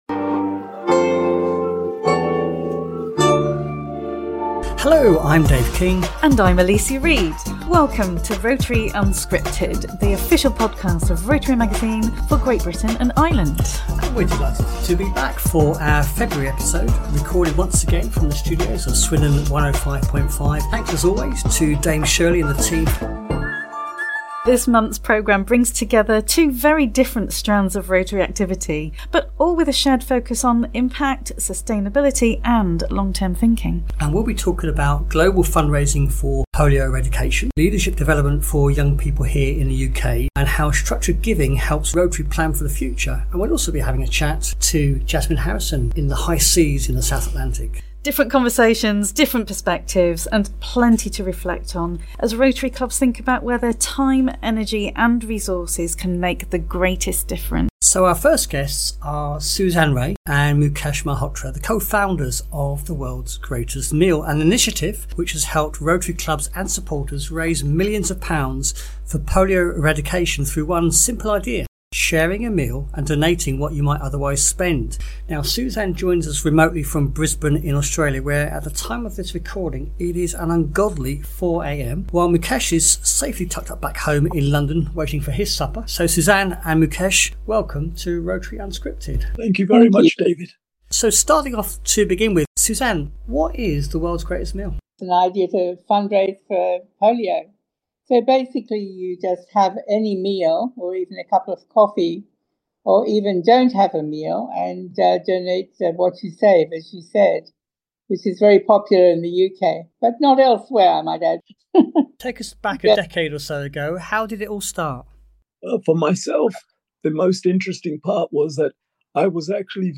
Unscripted brings together the voices, stories and insights that shape the Rotary movement from grassroots initiatives to global partnerships - every month.